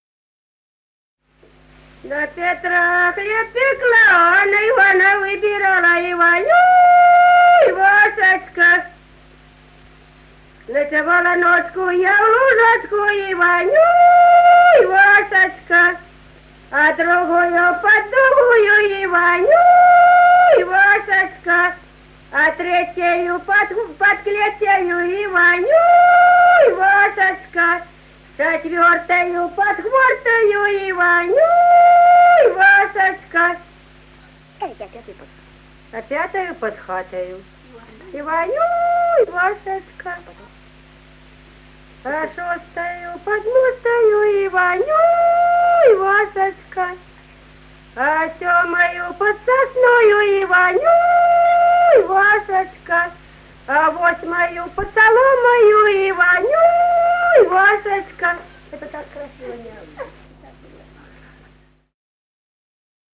Народные песни Стародубского района «На Петра хлеб пекла», купальская.
1959 г., с. Курковичи.